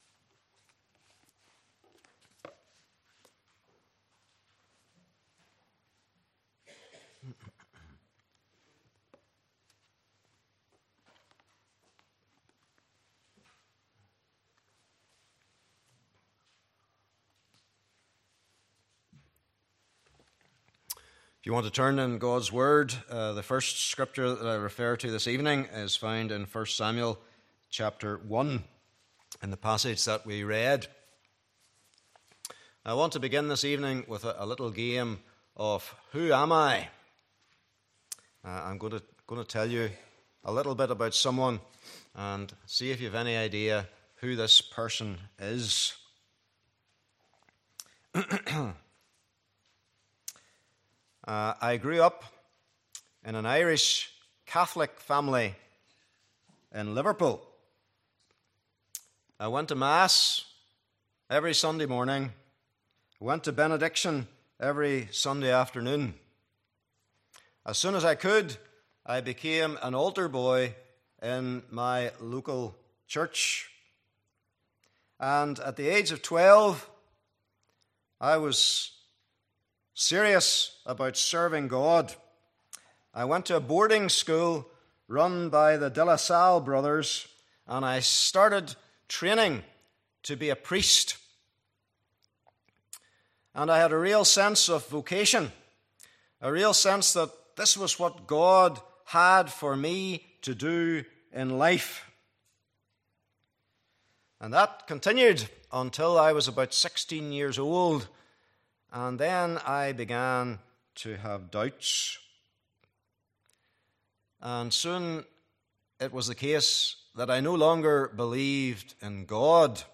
1 Samuel 1:1-18 Service Type: Evening Service Bible Text